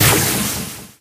mech_crow_atk_01.ogg